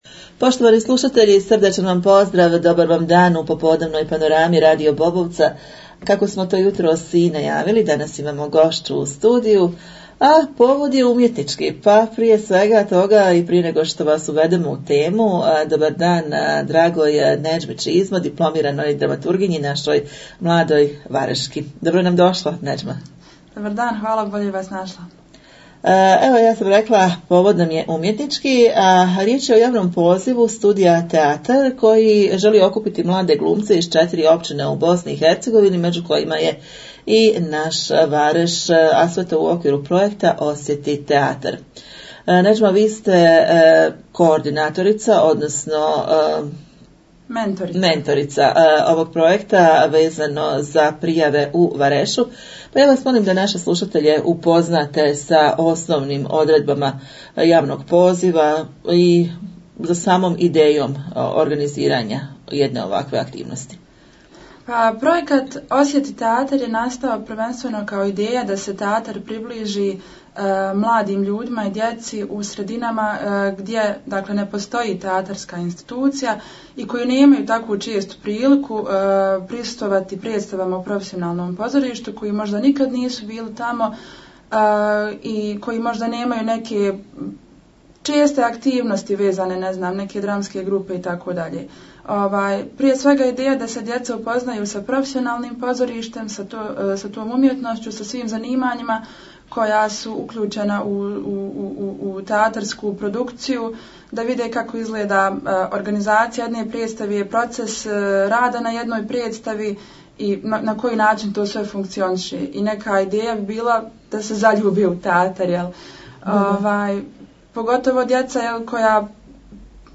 U studiju smo ugostili